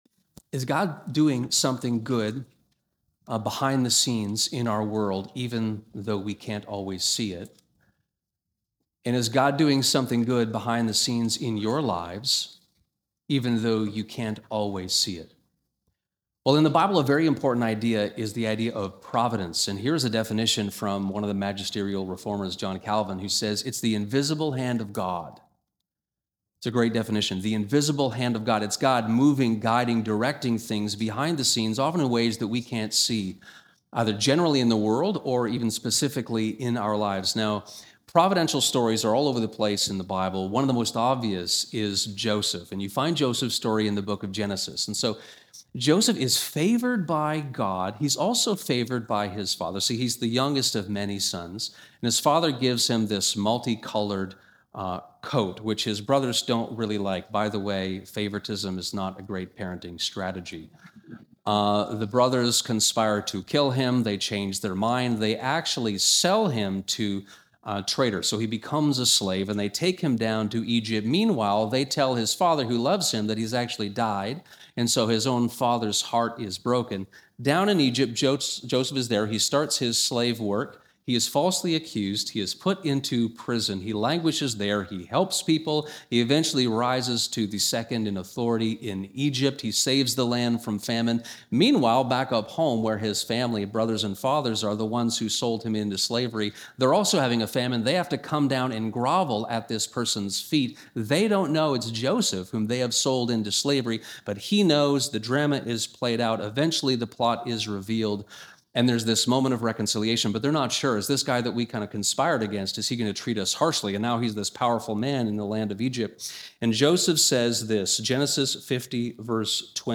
Born To Die That We Might Live: The Prophecy of the Coming King in Isaiah 53 (Sermon)